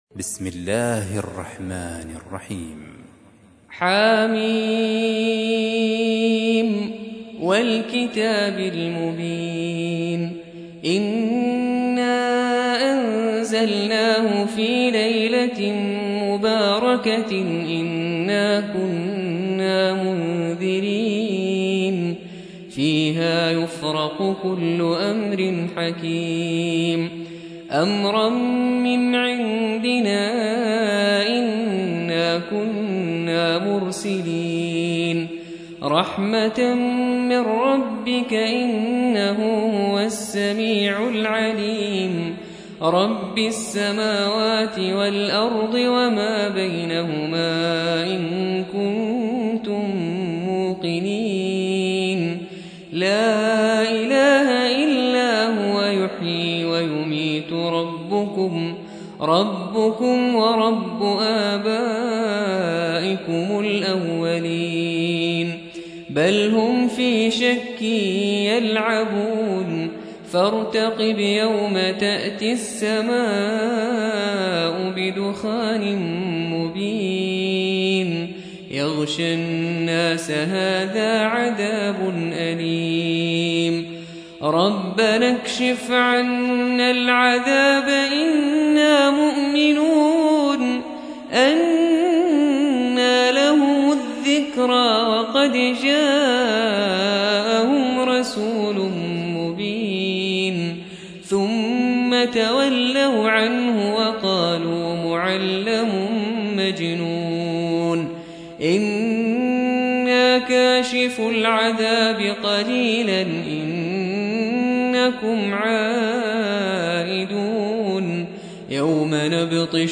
44. سورة الدخان / القارئ